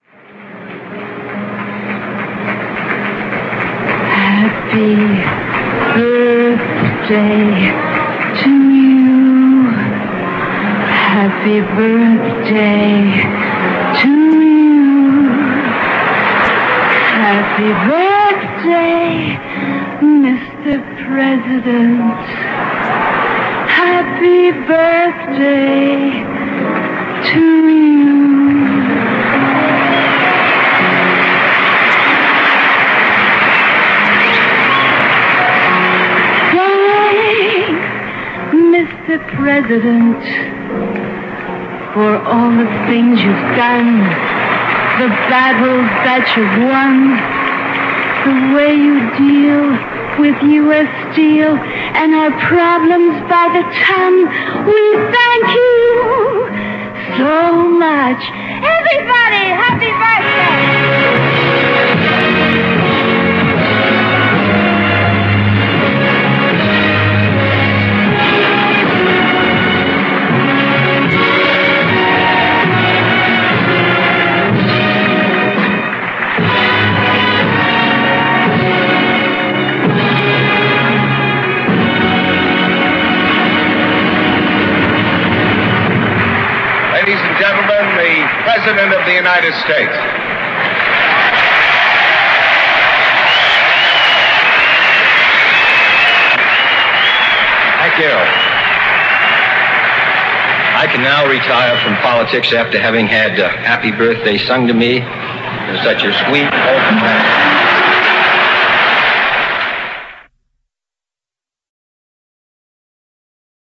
Marilyn Monroe canta " Feliz cunpleaños, Sr. Presidente" a John F. Kennedy